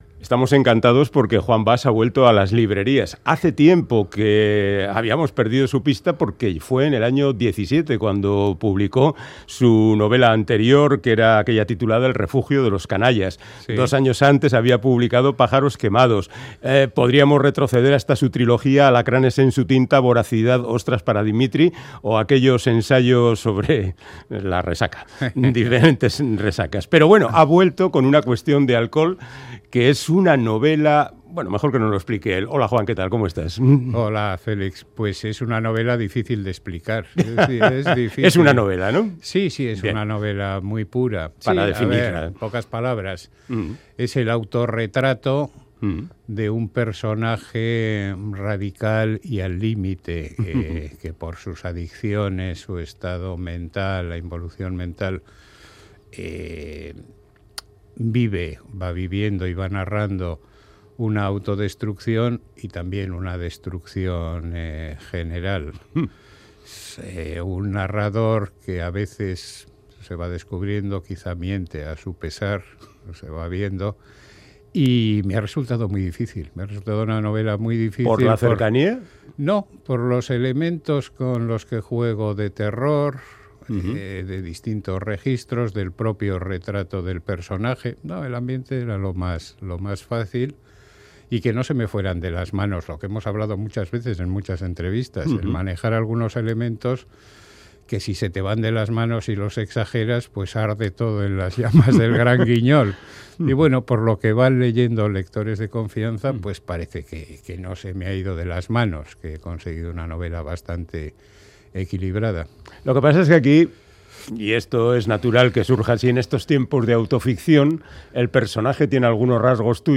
Audio: Charlamos con el bilbaíno Juan Bas sobre su novela "Una cuestión de alcohol" que habla de los problemas que atraviesan la vida de un alcohólico